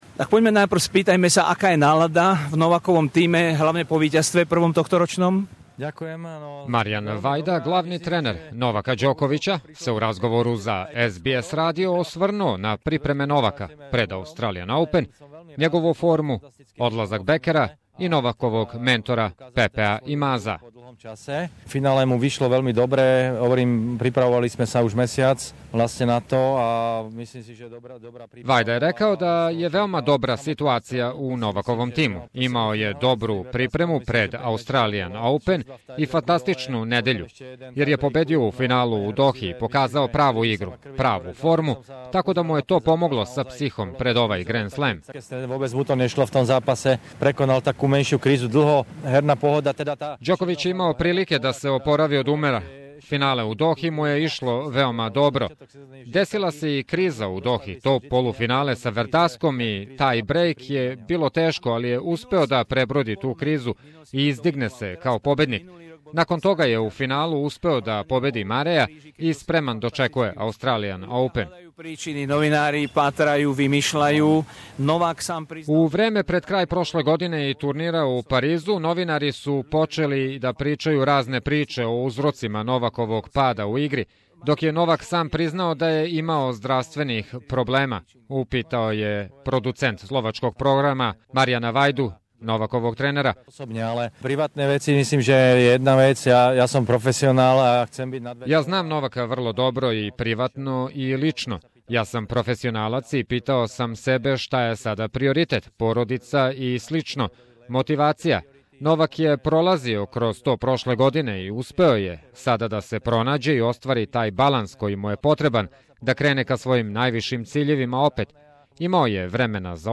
Original interview